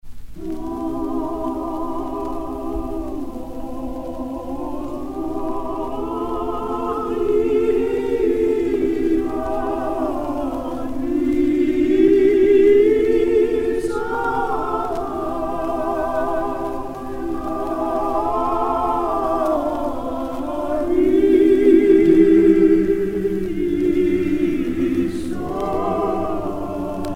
dévotion, religion
Pièce musicale éditée